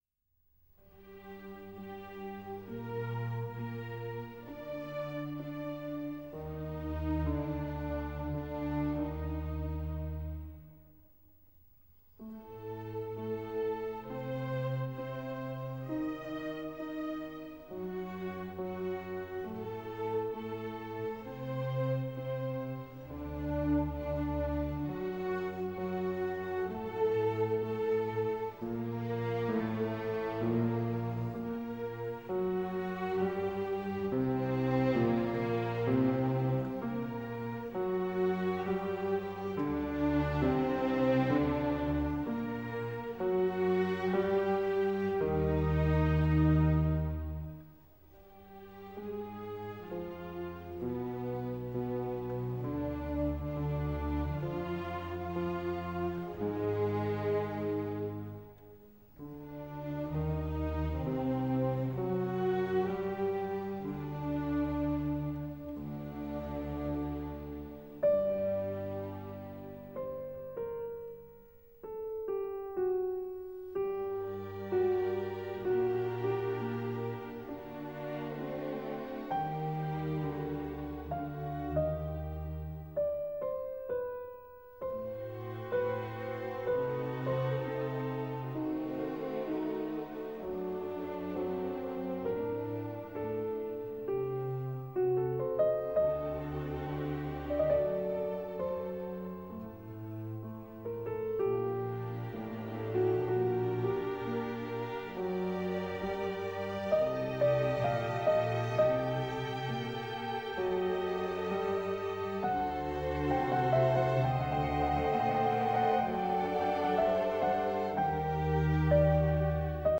02.Keyboard Concerto No.1 in d-moll
Adagio